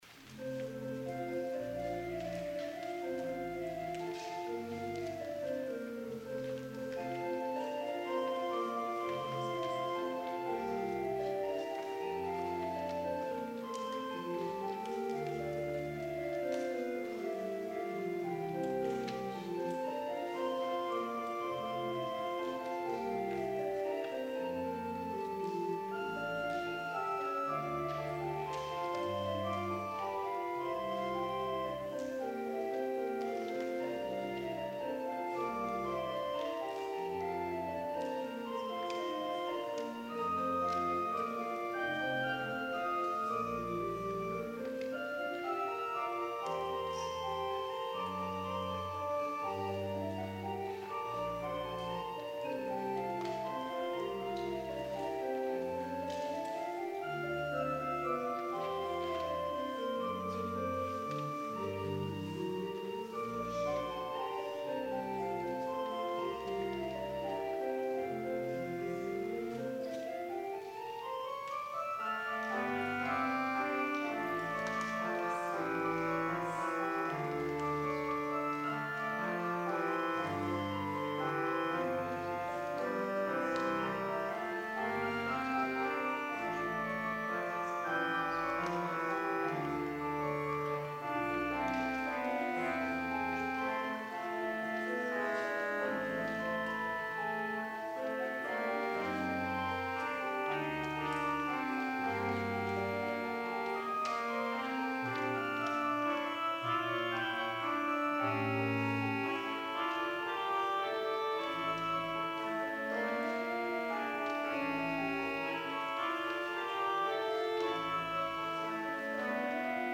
PRELUDE Allegretto
organ